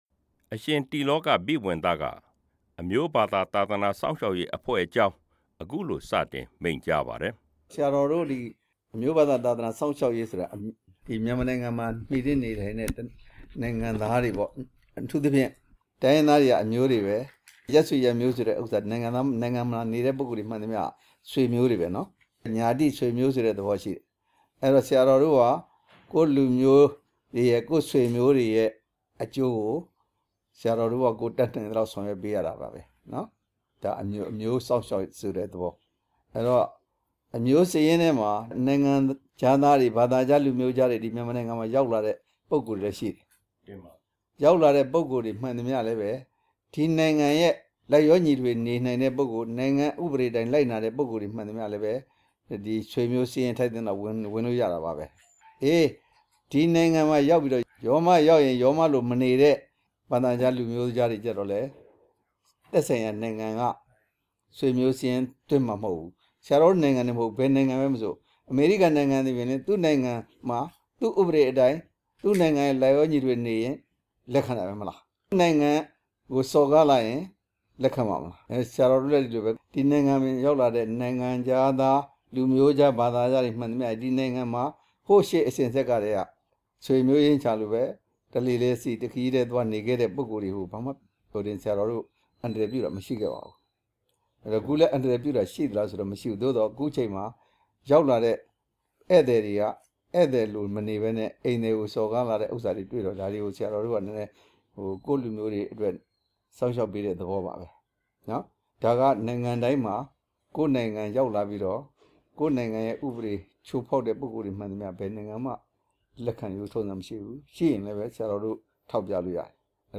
မဘသအဖွဲ့ ဥက္ကဌ ဆရာတော် အရှင်တိလောကာဘိဝံသနဲ့ မေးမြန်းချက်